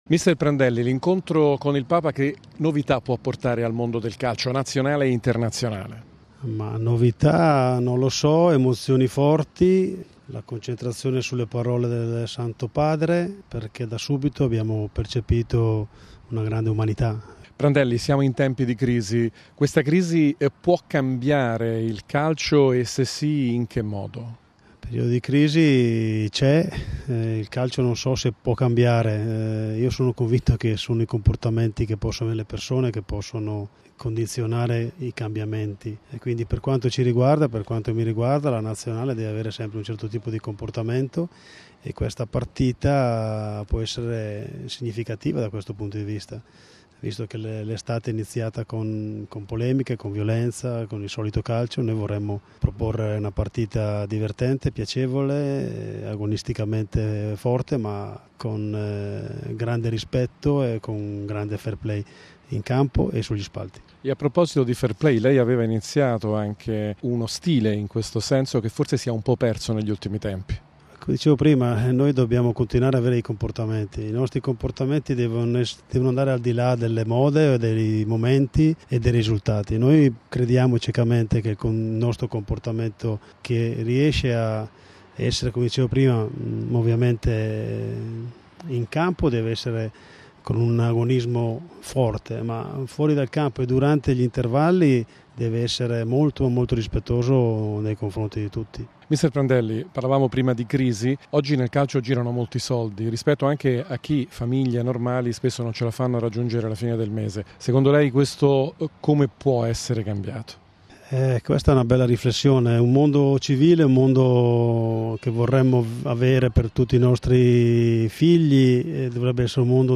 Mister Prandelli alla Radio Vaticana.